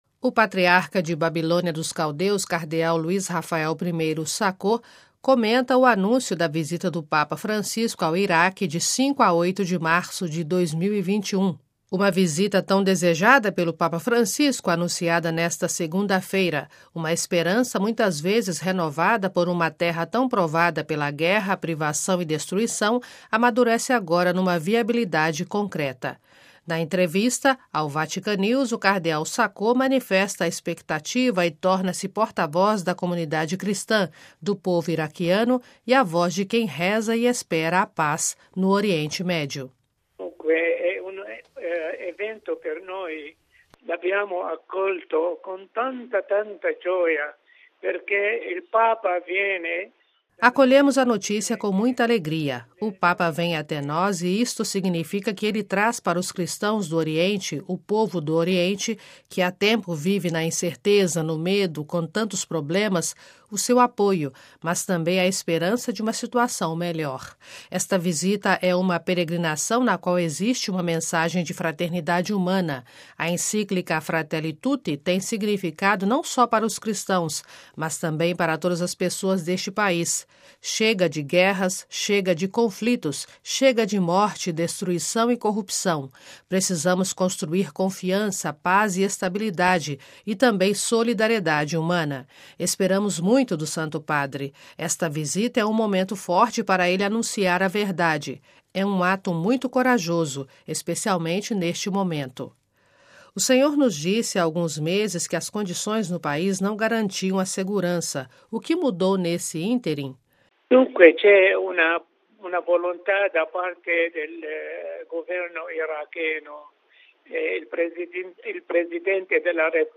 O patriarca de Babilônia dos Caldeus, cardeal Louis Raphaël I Sako, em nossa entrevista manifesta a expectativa e torna-se porta-voz da comunidade cristã, do povo iraquiano e a voz de quem reza e espera a paz no Oriente Médio.